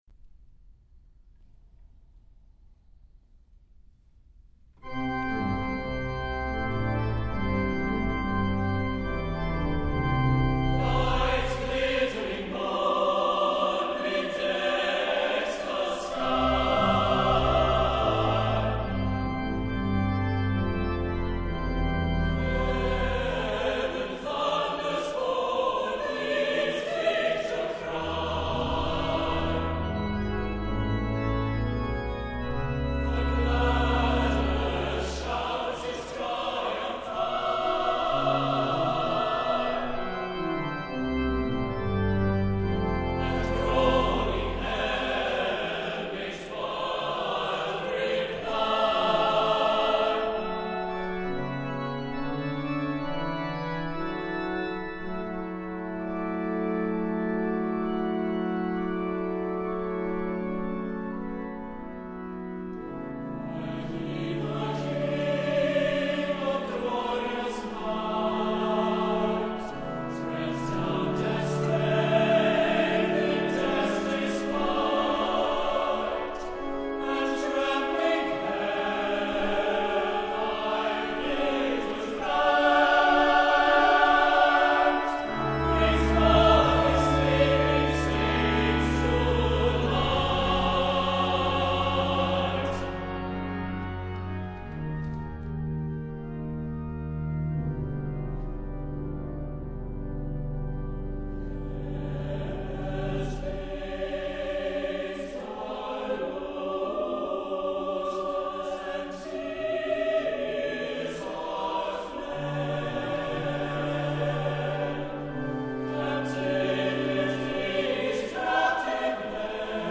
• Music Type: Choral
• Voicing: SATB
• Accompaniment: Brass Quintet, Organ